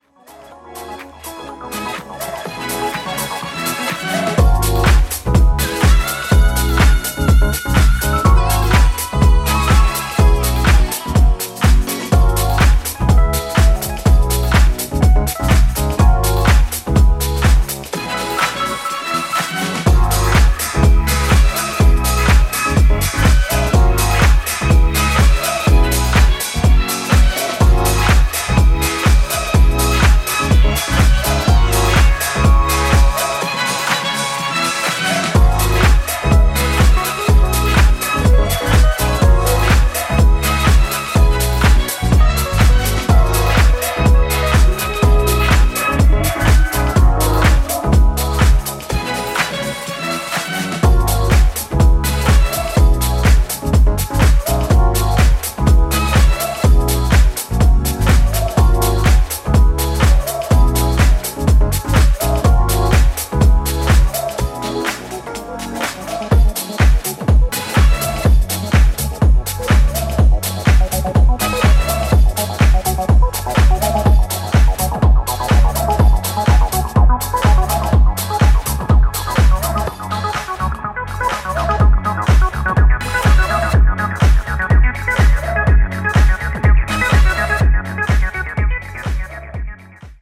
全4曲リズミカルで爽やかなディスコ＆ディープなハウスサウンドに仕上がった大スイセンの1枚です！！
ジャンル(スタイル) DISCO HOUSE